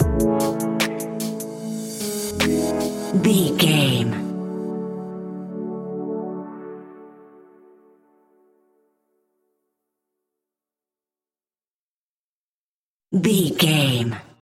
Ionian/Major
hip hop
chilled
laid back
hip hop drums
hip hop synths
piano
hip hop pads